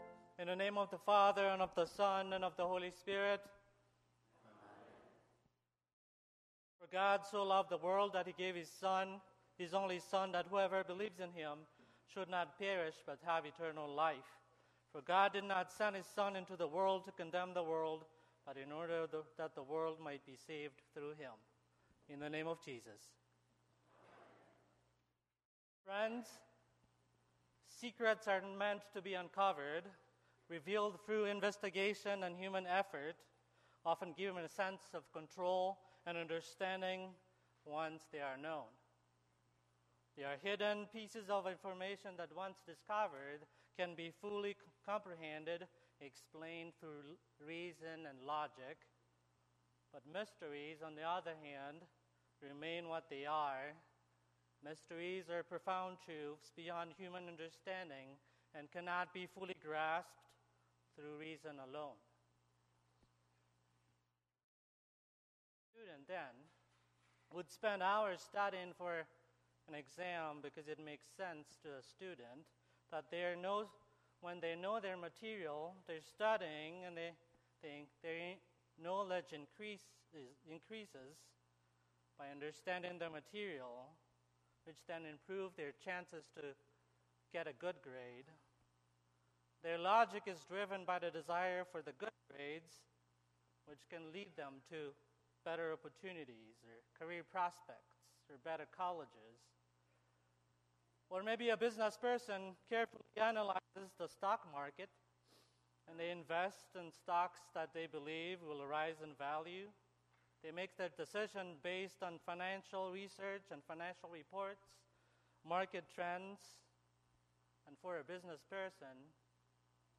Sermon - 5/26/2024 - Wheat Ridge Lutheran Church, Wheat Ridge, Colorado